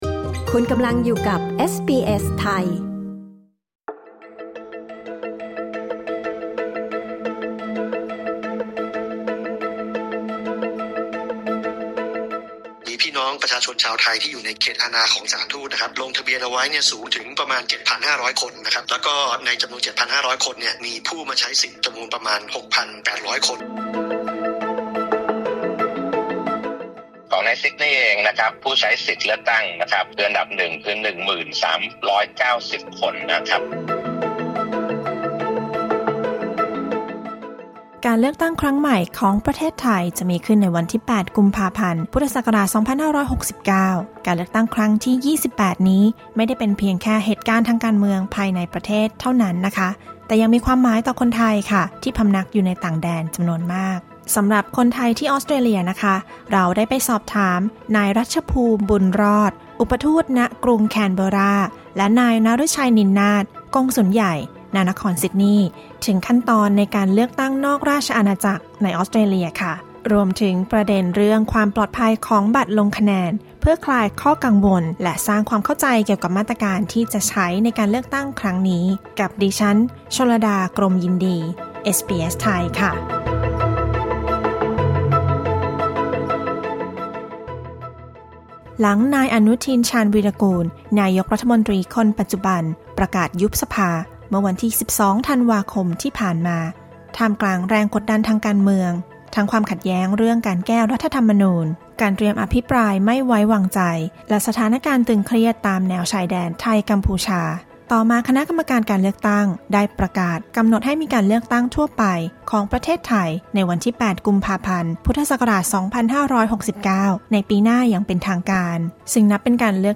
การเลือกตั้งทั่วไปของไทยครั้งที่ 28 จะมีขึ้นในวันที่ 8 กุมภาพันธ์ พ.ศ. 2569 ซึ่งมีความหมายต่อคนไทยทั้งในและนอกประเทศ เอสบีเอสไทยสอบถามกับนายรัชภูมิ บุญรอด อุปทูต ณ กรุงแคนเบอร์รา และนายนฤชัย นินนาท กงสุลใหญ่ ณ นครซิดนีย์ ถึงขั้นตอนการเลือกตั้งนอกราชอาณาจักรในออสเตรเลีย และมาตรการดูแลความปลอดภัยของบัตรลงคะแนน